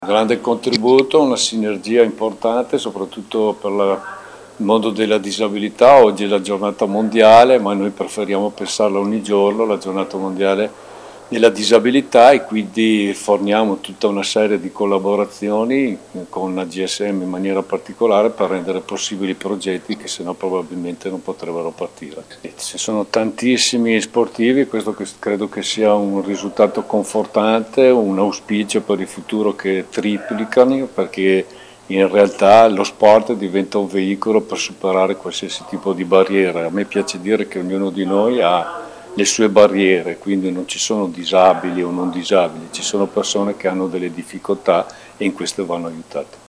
Stefano Bertacco, assessore ai servizi sociali del comune di Verona:
Stefano-Bertacco-assessore-ai-servizi-sociali-del-comune-di-Verona.mp3